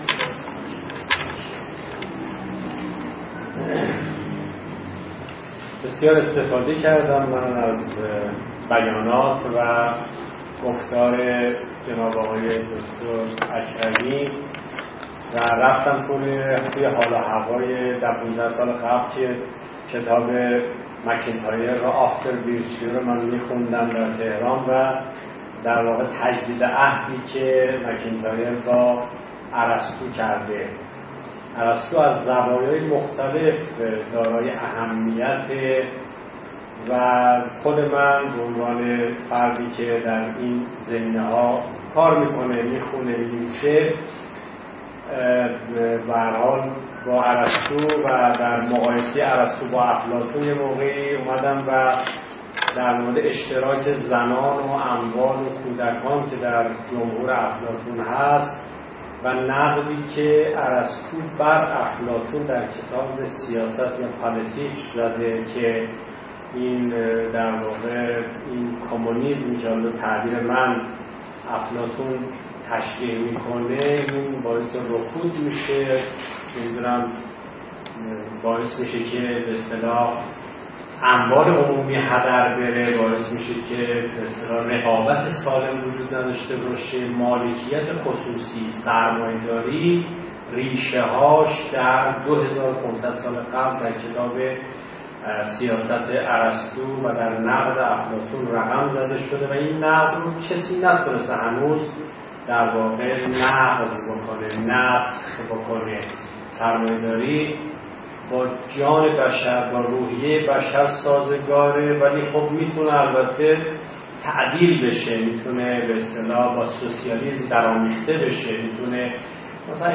این نشست به همت انجمن علوم سیاسی و خانه اندیشمندان علوم انسانی چهارشنبه ۱۸ شهریور ماه ۹۴ در خانه اندیشمندان علوم انسانی برگزار شد.